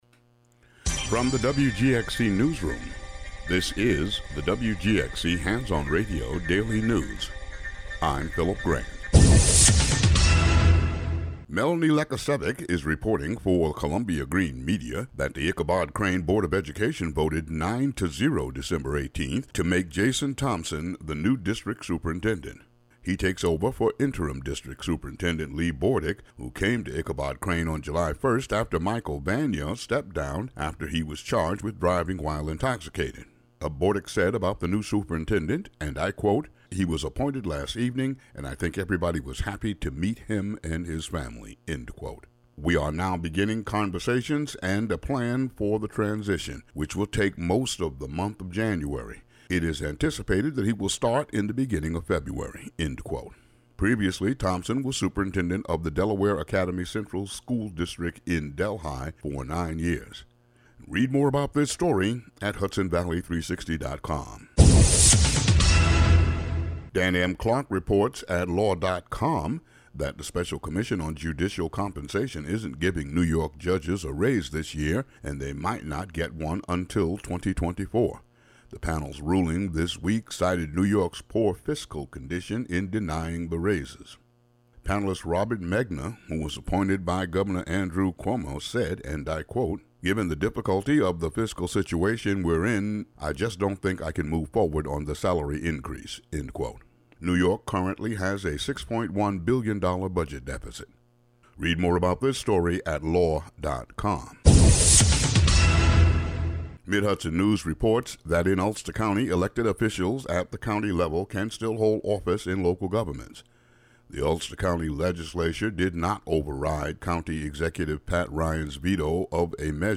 The audio version of the local news for Fri., Dec. 20.